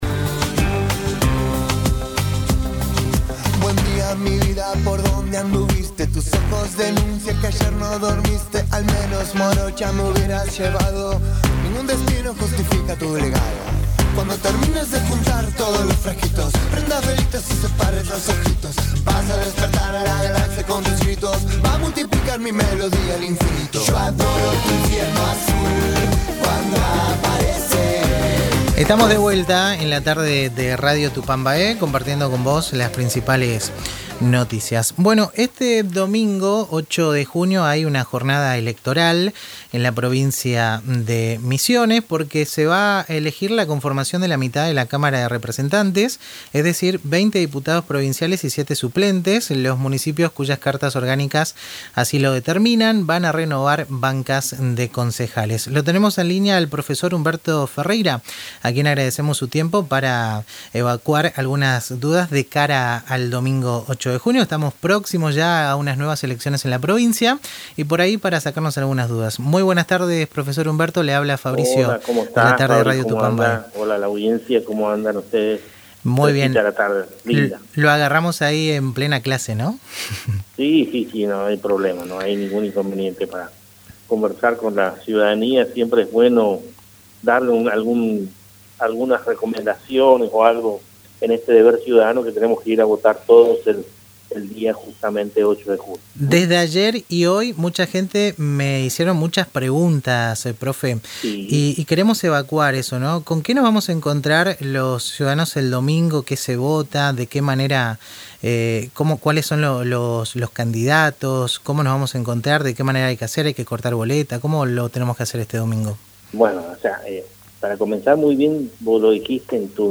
En el programa El Ritmo Sigue, por Radio Tupambaé